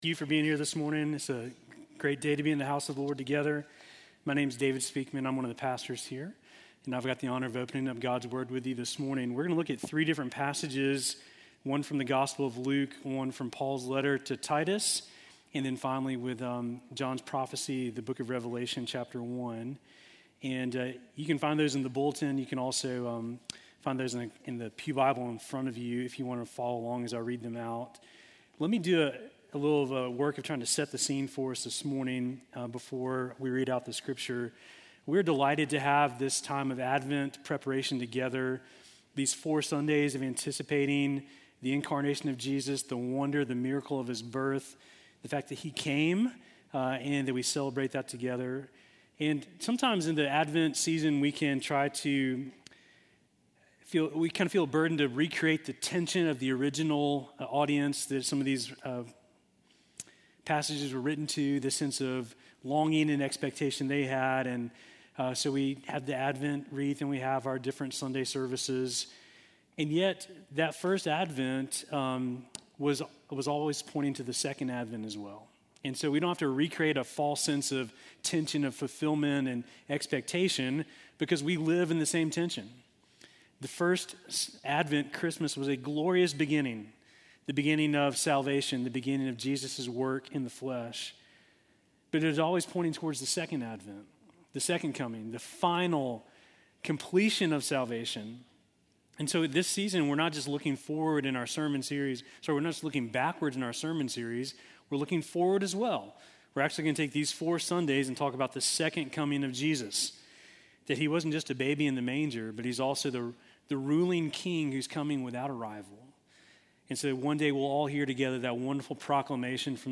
Sermon from November 30